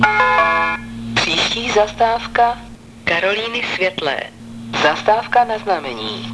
Hlášení zastávek ústecké MHD
Většina nahrávek byla pořízena ve voze ev.č. 516.
Na této stránce jsou kvalitnější zvuky, původní web obsahuje nahrávky horší zvukové kvality.